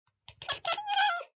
sounds / mob / cat / beg1.ogg